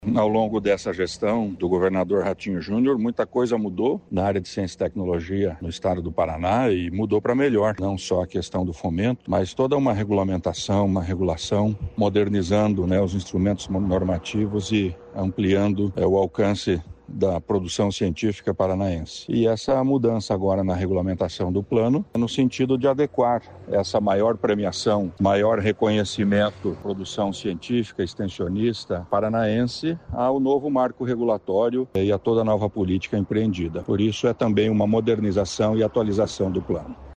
Segundo o secretário estadual da Ciência, Tecnologia e Ensino Superior, Aldo Nelson Bona, sobre o prêmio estadual na área de Ciência e Tecnologia